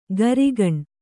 ♪ garigaṇ